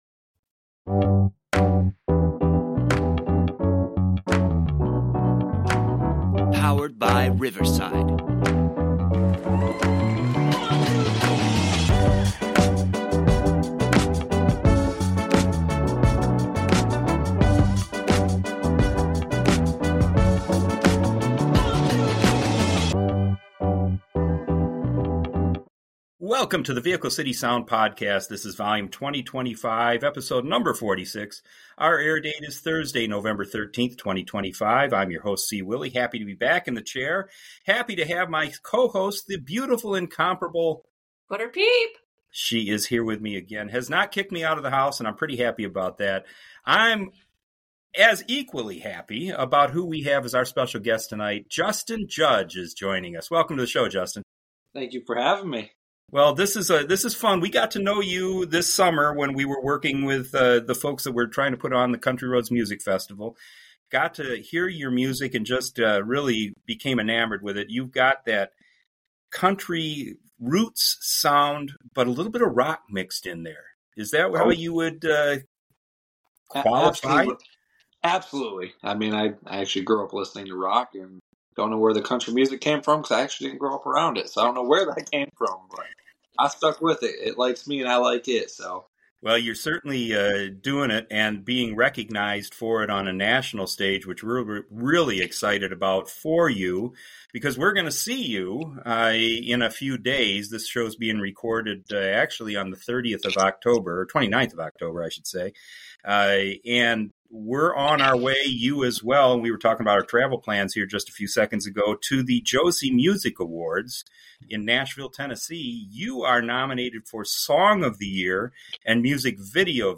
His modern country sound and genuine story telling will draw you in.&nbsp